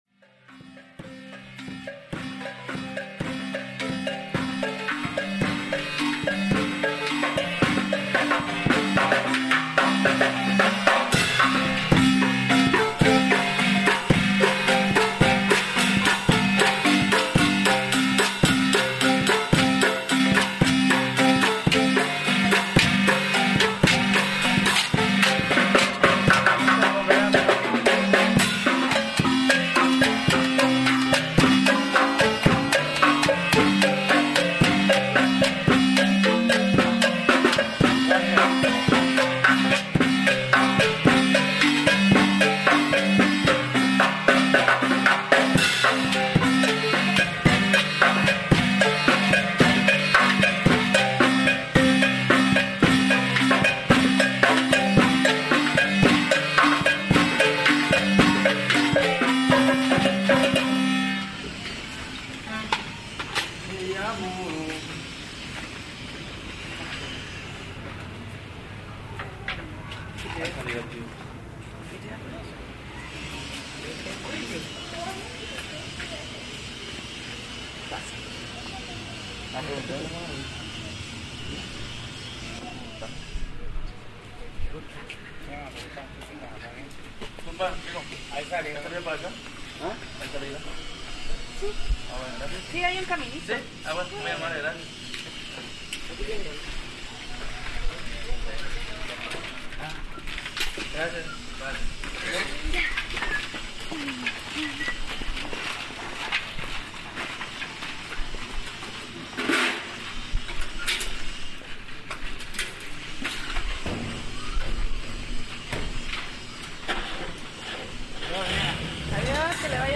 Marimba en la calle
Cinco hombres recorren las calles de la ciudad cargando una sencilla marimba y otros instrumentos, se cobijan en cada sombra de un árbol o de una casa para entonar una melodía.
La nostalgia se adueña de cada espacio, las personas se asoman curiosas y hasta piden alguna canción que desean escuchar; al termino de la ejecución, uno de ellos pasa con un sombrero pidiendo unas monedas.
Equipo: Grabadora Sony ICD-UX80 Stereo.